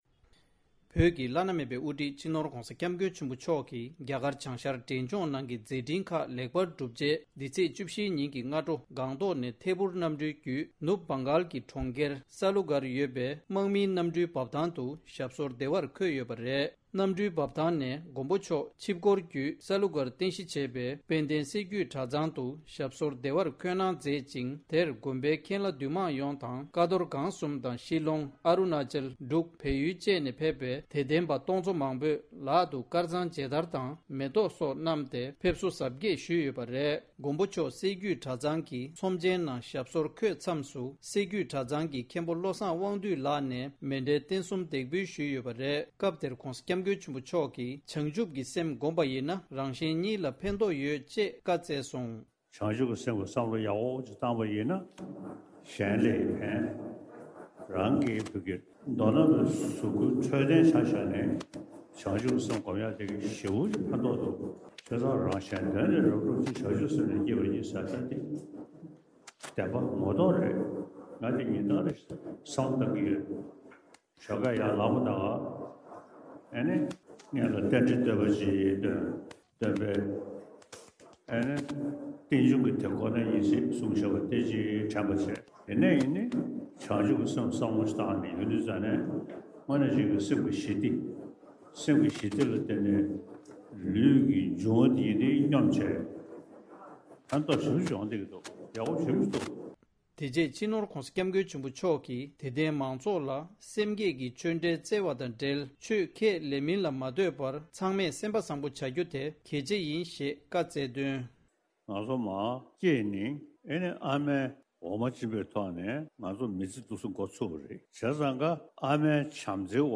༸གོང་ས་མཆོག་གིས་བྱང་ཆུབ་ཀྱི་སེམས་བསྒོམ་པ་ཡིན་ན་རང་གཞན་གཉིས་ལ་ཕན་ཐོགས་ཡོད་ཅེས། བཀའ་བསྩལ་བ། ༸གོང་ས་མཆོག་གིས་སྲད་རྒྱུད་གྲྭ་ཚང་དུ་བཀའ་ཆོས་སྩལ་བའི་སྐབས། ༢༠༢༣།༡༢།༡༤ ཉིན།
སྒྲ་ལྡན་གསར་འགྱུར།